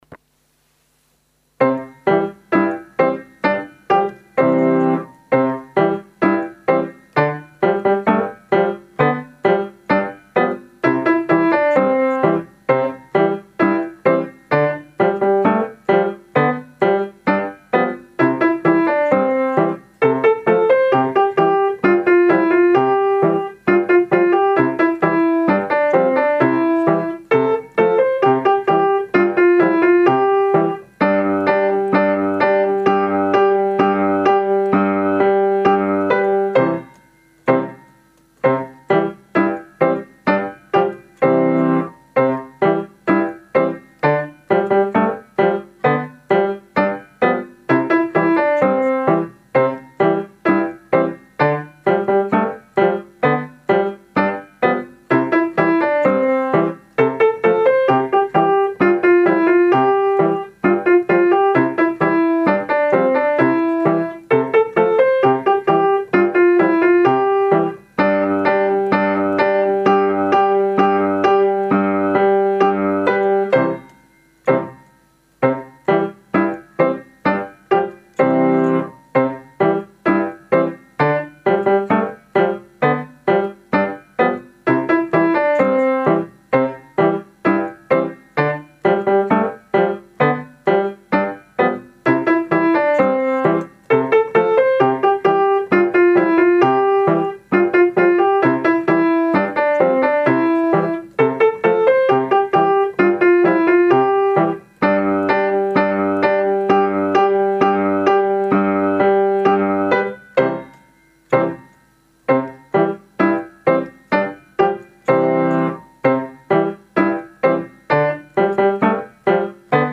伴奏音源